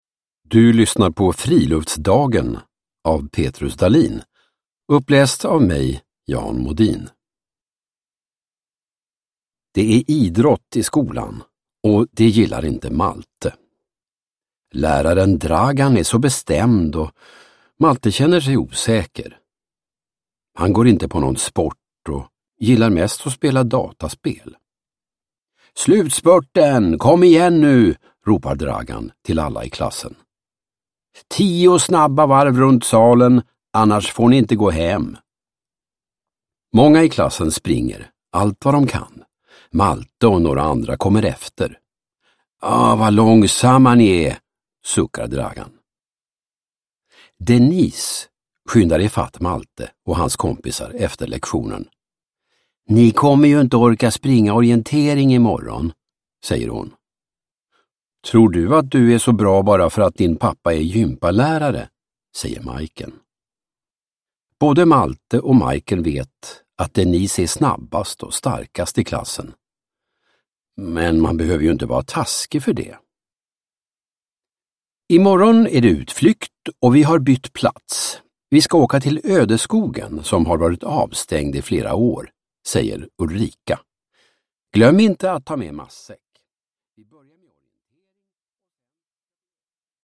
Friluftsdagen – Ljudbok